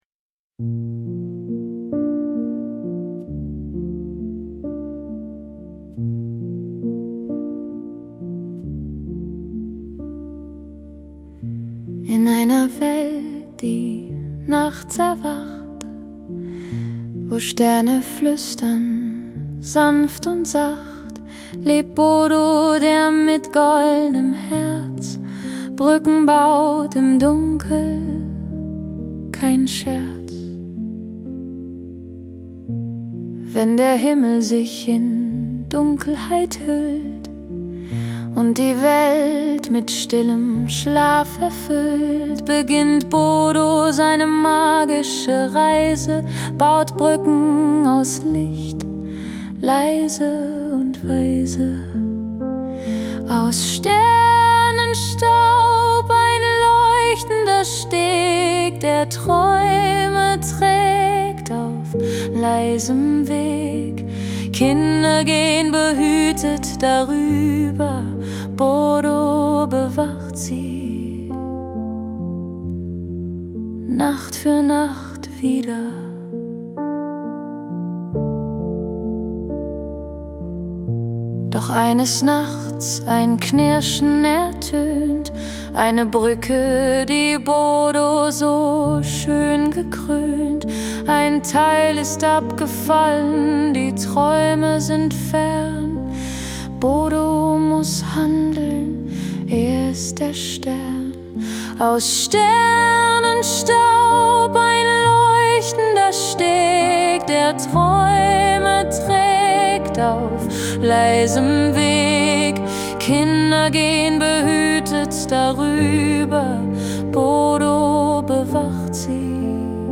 Das Schlaflied zur Geschichte